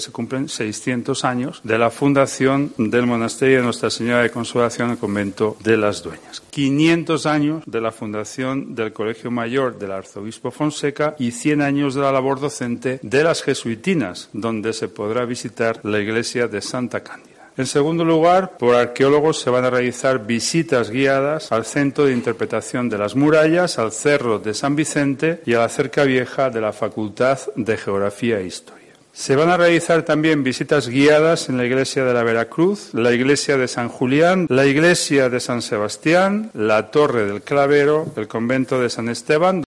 El alcalde Carlos García Carbayo informa sobre las actividades del programa cultural